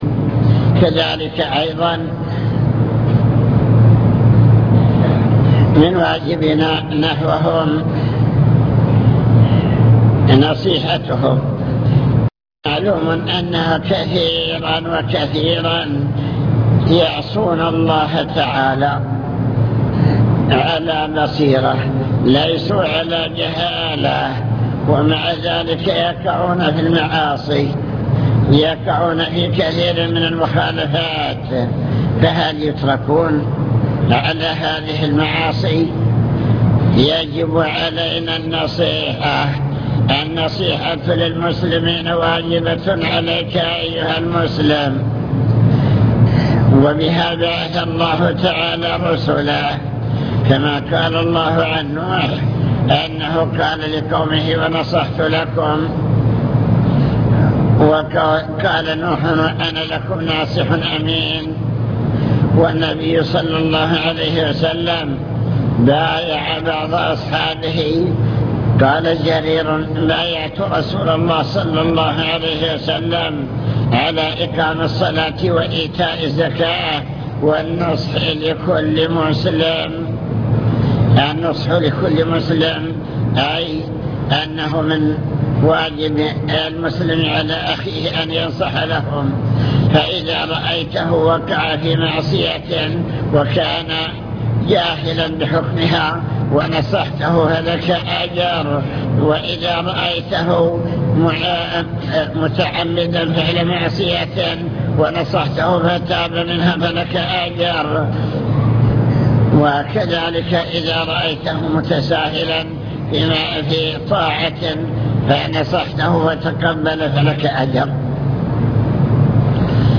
المكتبة الصوتية  تسجيلات - محاضرات ودروس  محاضرة في السعيرة